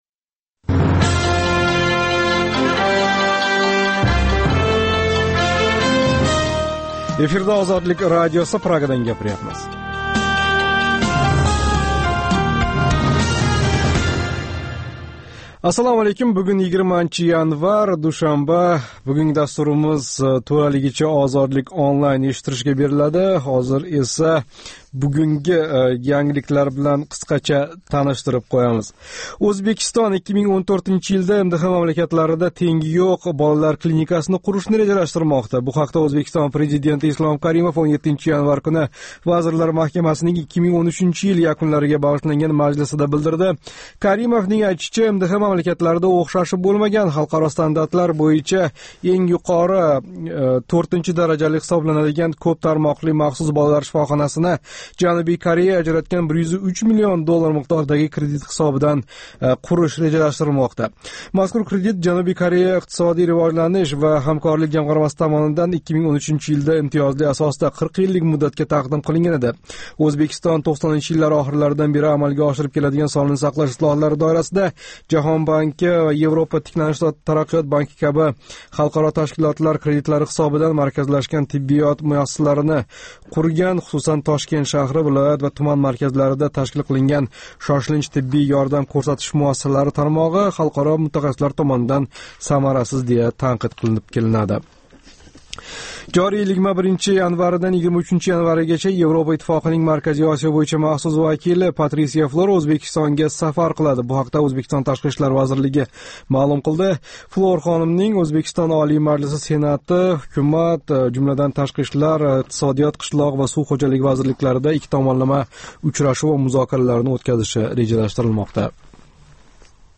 “Ozodlik Online” - Интернетдаги энг замонавий медиа платформаларни битта тугал аудио дастурга бирлаштирган Озодликнинг жонли интерактив лойиҳаси.